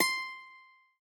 lute_c1.ogg